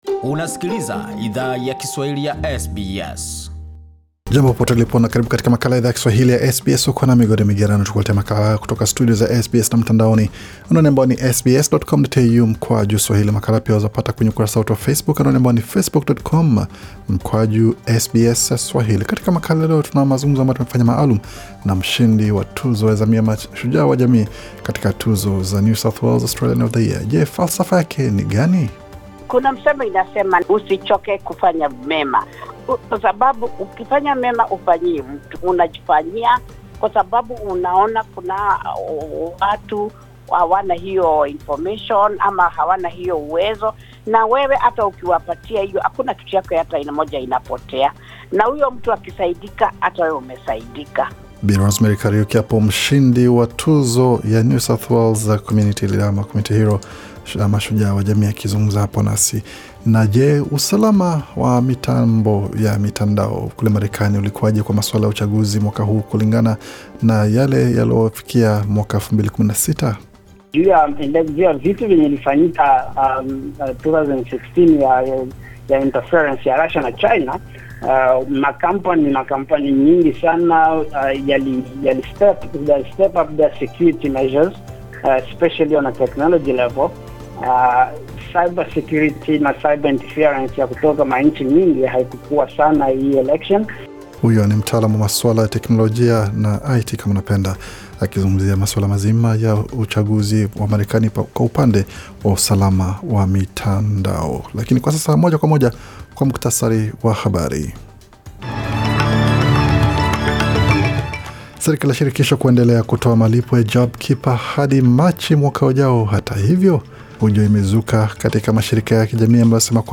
Taarifa ya habari 10 Novemba 2020